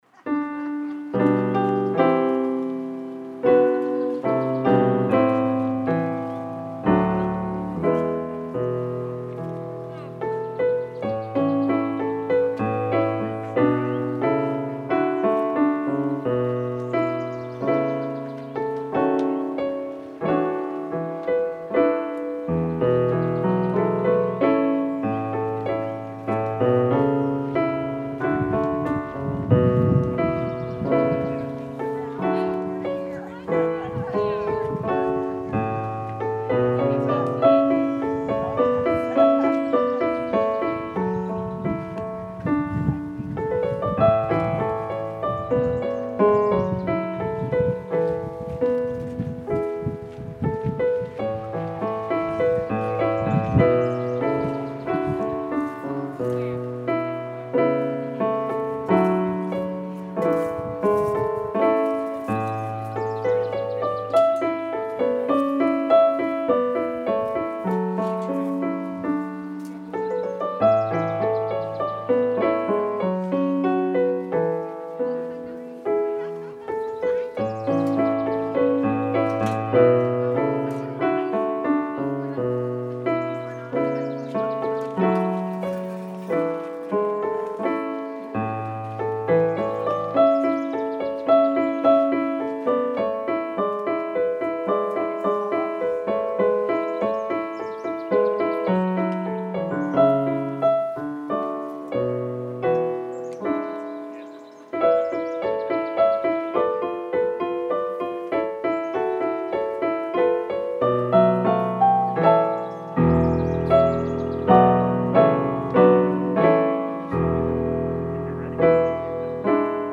We joined for worship on Sunday, May 9, 2021 at 10:00AM!